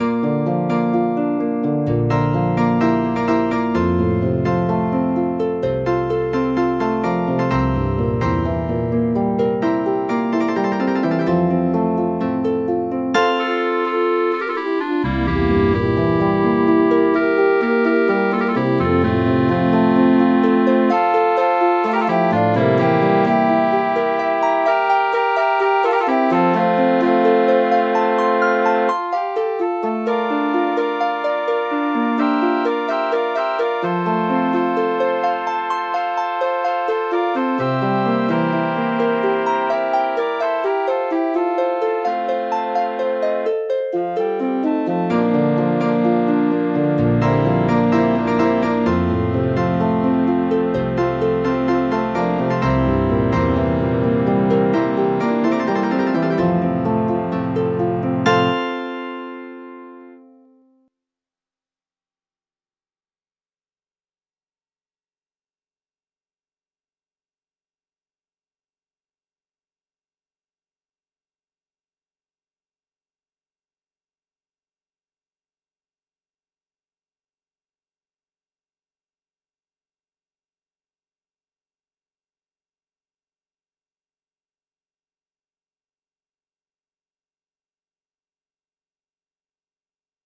Фонограма-мінус (mp3)